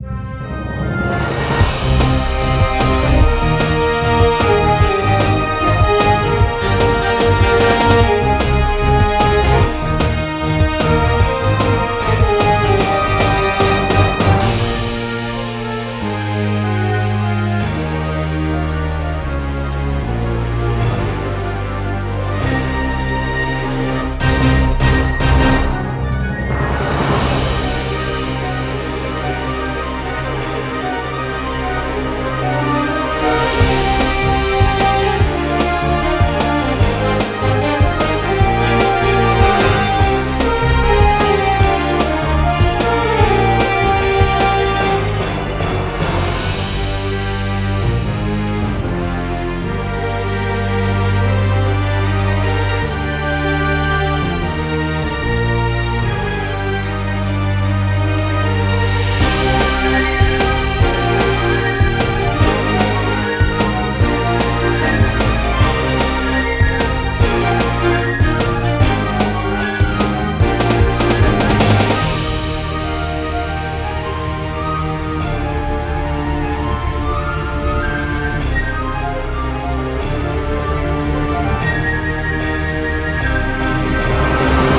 壮大なボス戦を想定して制作しました。オーケストラBGM